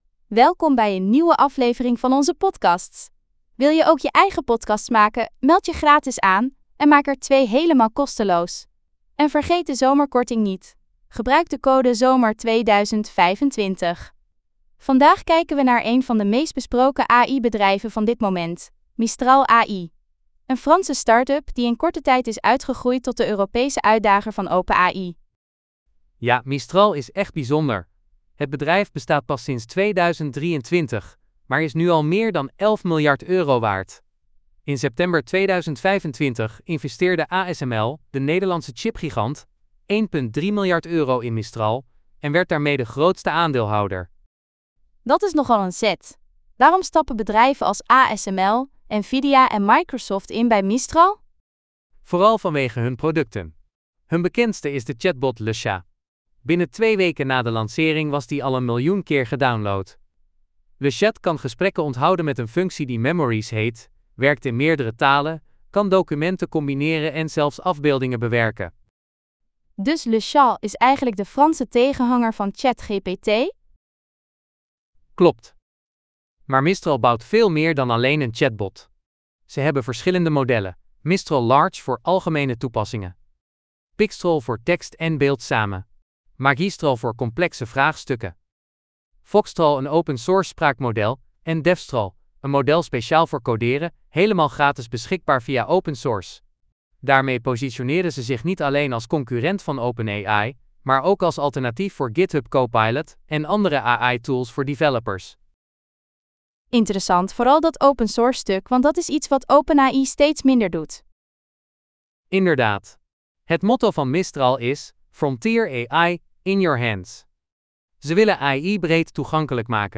Podcast gegenereerd van geüploade script: 🚀 𝗠𝗶𝘀𝘁𝗿𝗮𝗹 𝗔𝗜: 𝗱𝗲 𝗘𝘂𝗿𝗼𝗽𝗲𝘀𝗲 𝘂𝗶𝘁𝗱𝗮𝗴𝗲𝗿 𝘃𝗮𝗻 𝗢𝗽𝗲𝗻𝗔𝗜.